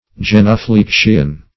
genuflection \gen`u*flec"tion\, genuflexion \gen`u*flex"ion\, n.